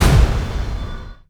sfx_boss.wav